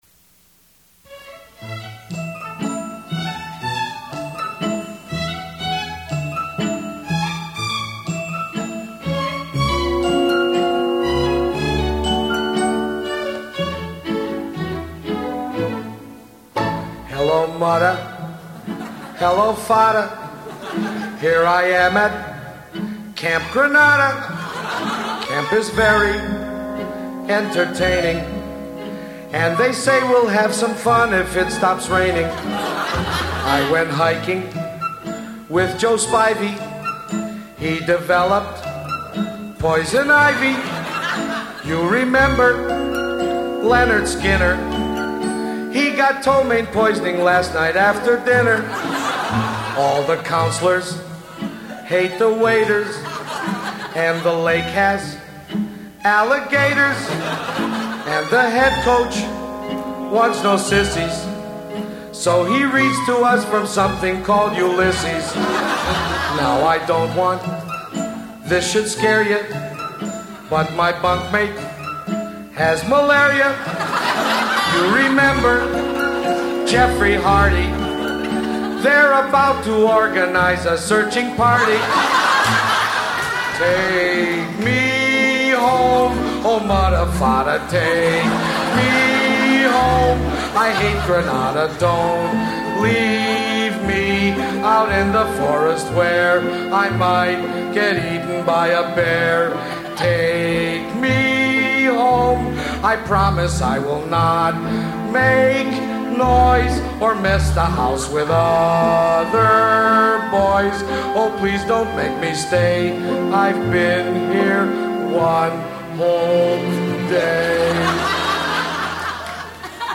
Tags: Comedy